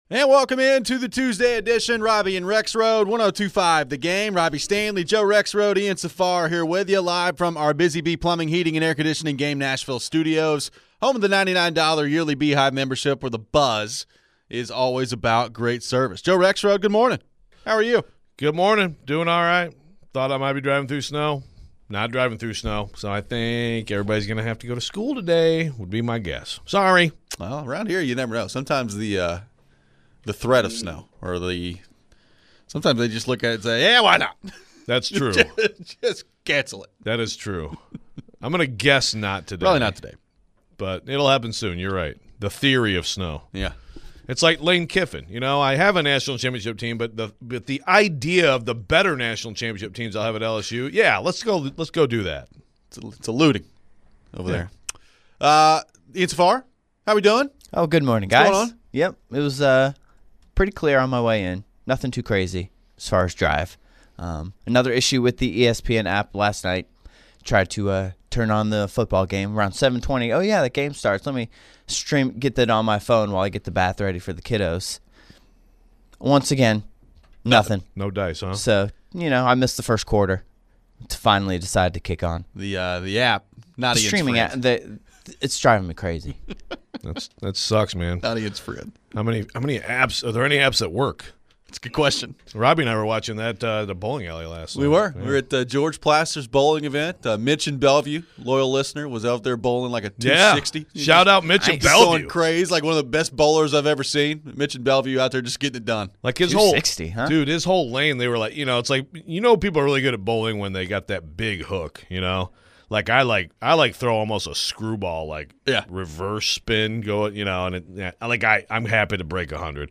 We head to your phones.